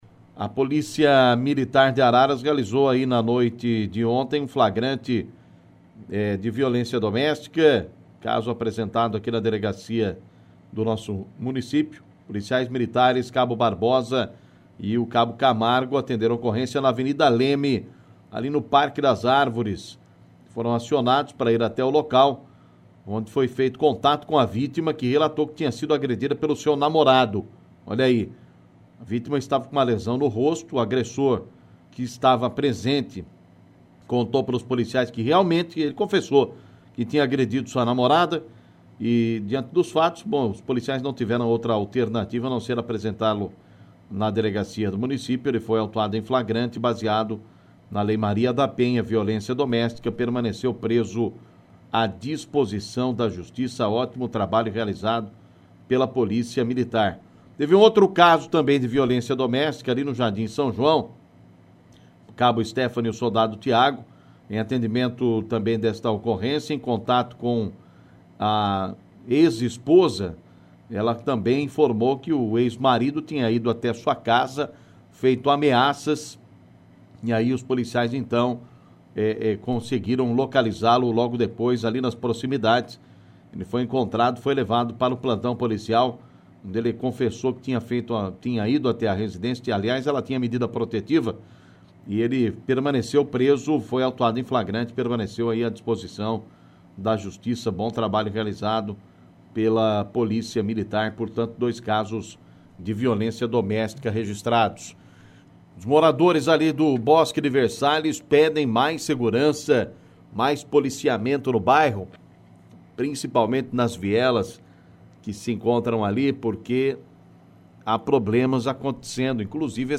Clique no link abaixo e ouça as principais notícias ocorridas em Araras e região na voz do repórter policial